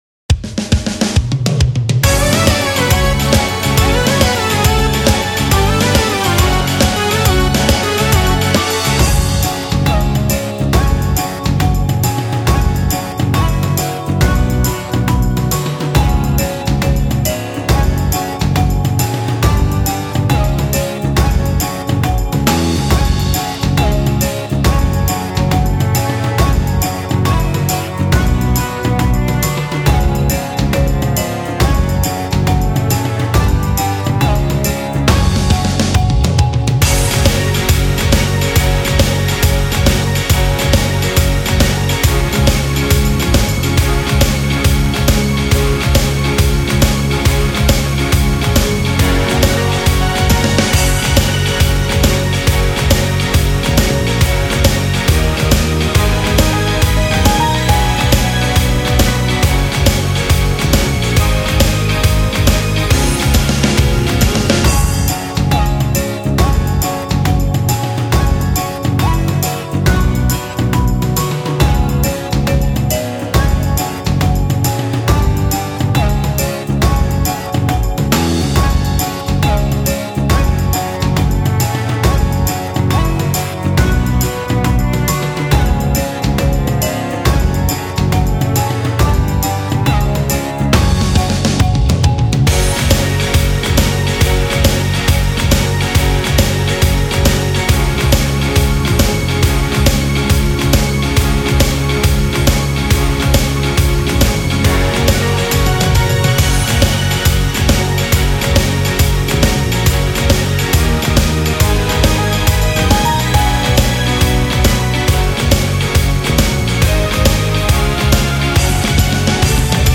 BASE VIDEO KARAOKE